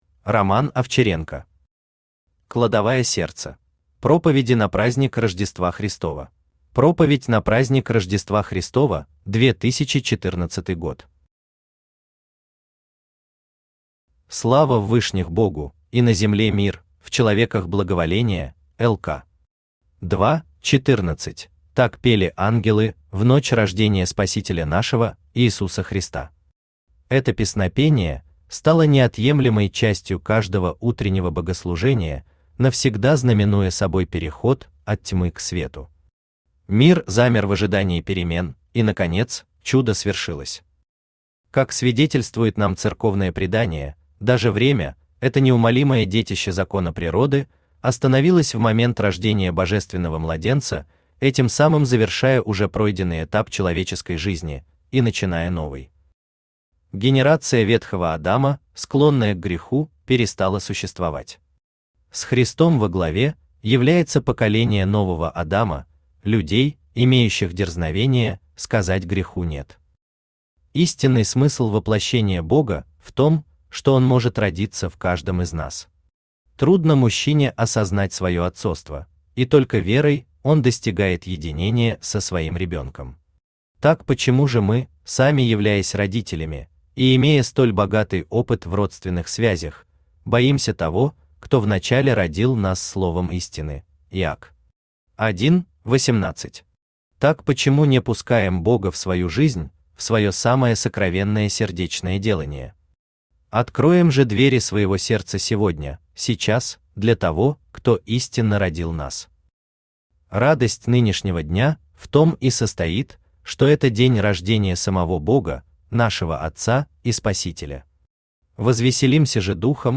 Аудиокнига Кладовая сердца | Библиотека аудиокниг
Aудиокнига Кладовая сердца Автор Роман Викторович Овчаренко Читает аудиокнигу Авточтец ЛитРес.